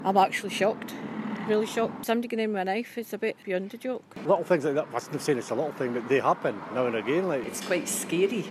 LISTEN: People in Hawick respond to raid on Howegate bakery